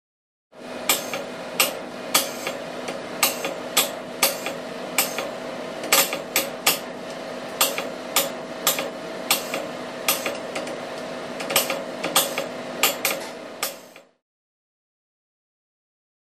Spectrograph; Sporadic Mechanical Clicks Leading Shorts Air Releases; Above Mechanical Drone, Close Perspective. Pressurized Gas.